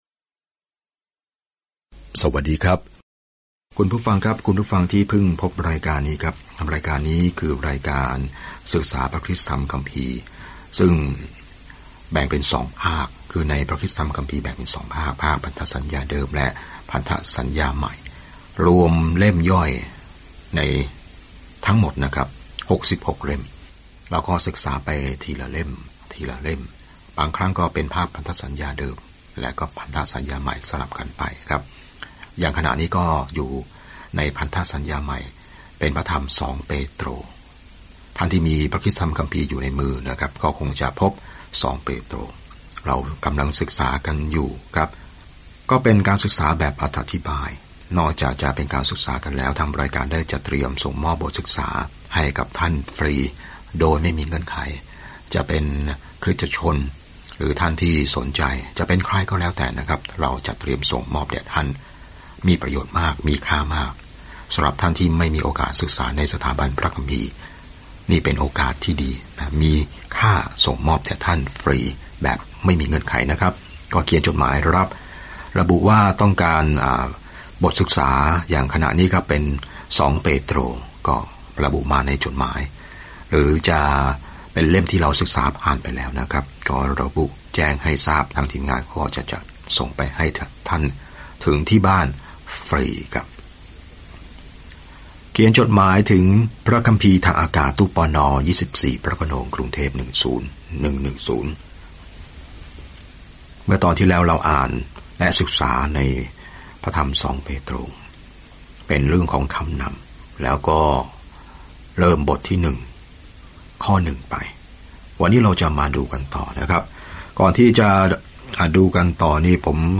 จดหมายฉบับที่สองจากเปโตรเป็นเรื่องเกี่ยวกับพระคุณของพระเจ้า - วิธีที่พระองค์ทรงช่วยเรา วิธีที่ทรงพิทักษ์เรา และวิธีที่เราจะมีชีวิตอยู่ในนั้น - แม้ว่าผู้สอนเท็จจะพูดอะไรก็ตาม เดินทางทุกวันผ่าน 2 เปโตรในขณะที่คุณฟังการศึกษาด้วยเสียงและอ่านข้อที่เลือกจากพระวจนะของพระเจ้า